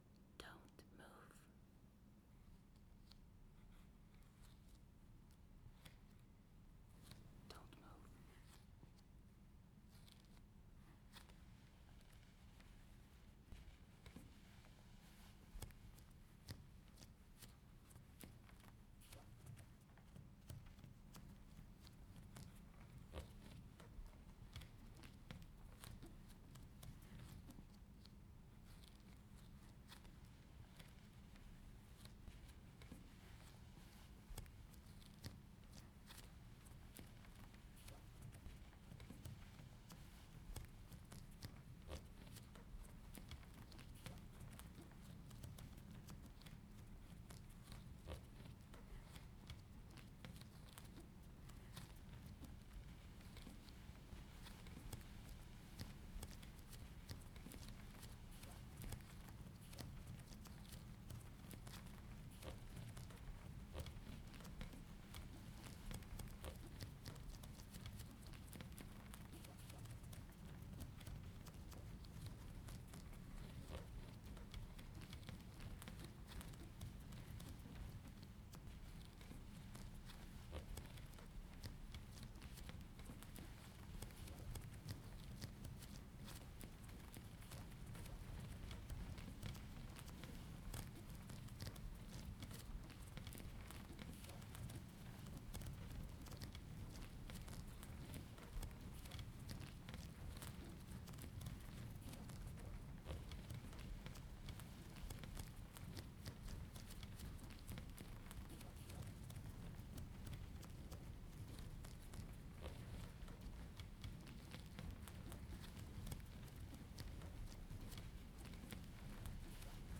phantom steps
PhantomSteps.mp3
CA_IDNO en -00146 Title en phantom steps Description en Listen with headphones. ContentConcept en ASMR en binaural en ZoomH2N File Date en 2025-10-04 Type en Audio Tier en 8.